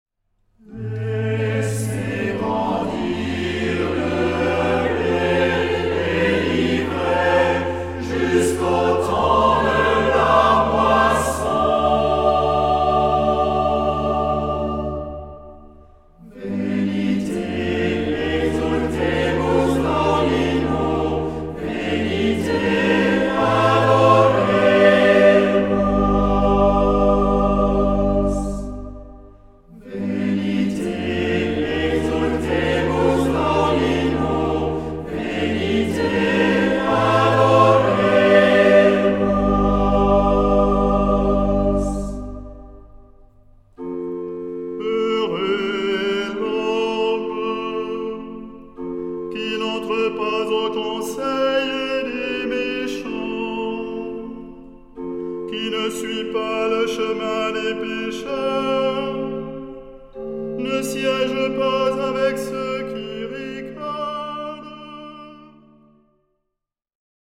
Genre-Style-Forme : Sacré ; Tropaire ; Psaume
Caractère de la pièce : recueilli
Type de choeur : SATB  (4 voix mixtes )
Instruments : Orgue (1)
Tonalité : sol (centré autour de)